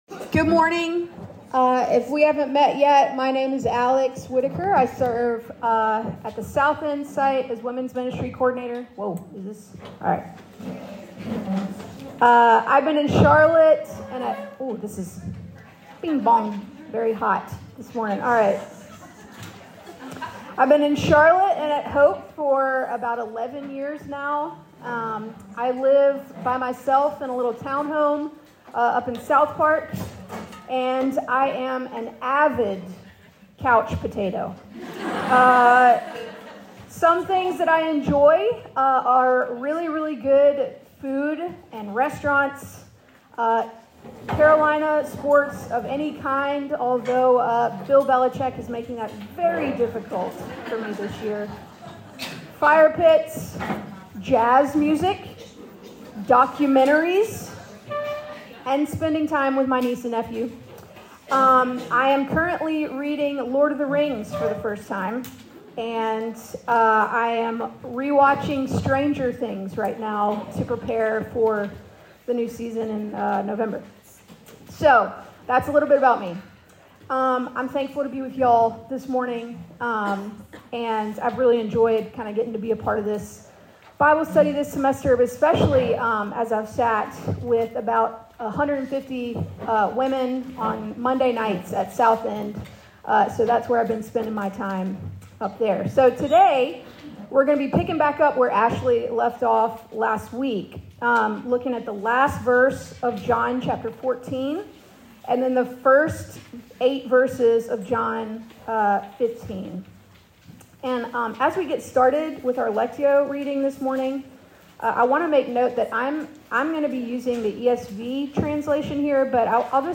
From Location: "Women's Bible Study"